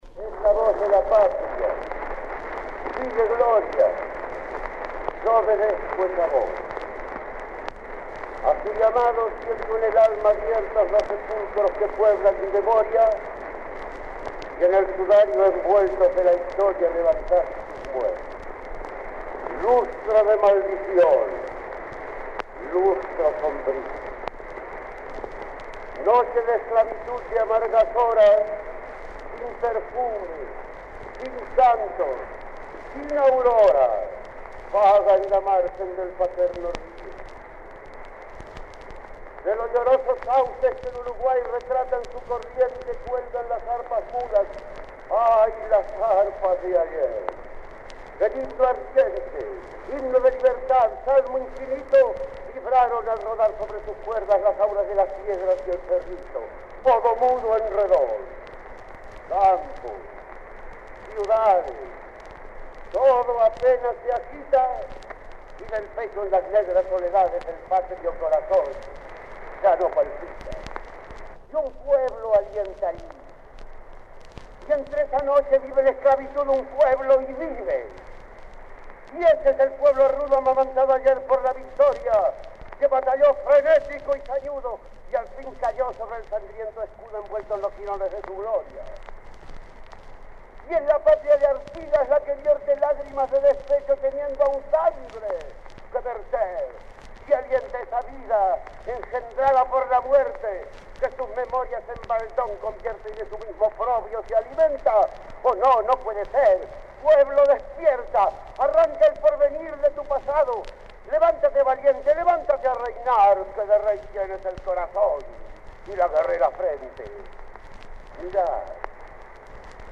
Recitados de Juan Zorrilla de San Martín declamando la Leyenda Patria.
Fueron tomados de un disco de cera que se convirtió a disco de pasata (anterior al vinilo) con gran trabajo de limpieza de ruidos y que en 1975 se publicaron en una edición especial en el Sesquicentenario de los Hechos Históricos de 1825.